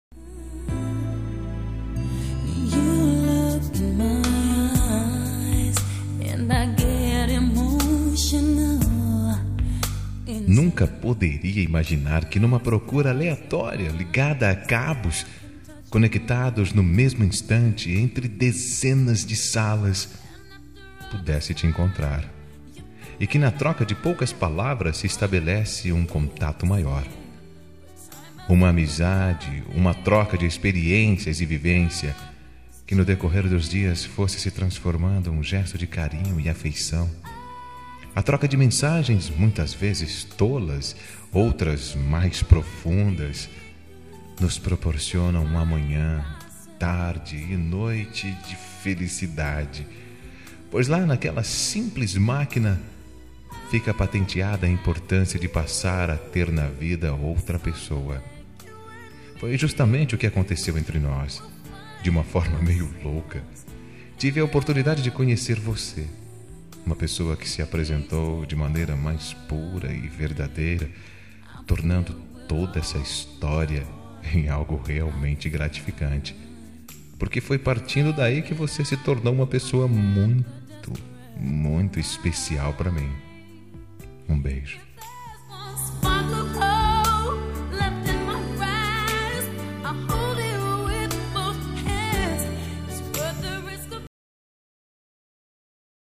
Telemensagem Virtual – Voz Masculina – Cód: 60210